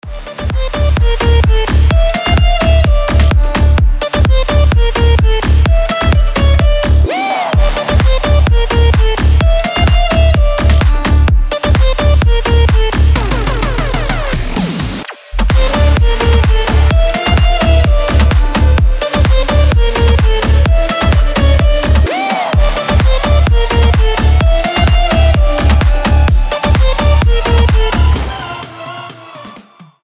• Качество: 320, Stereo
гитара
громкие
восточные мотивы
мелодичные
веселые
EDM
electro house
Восточный electro house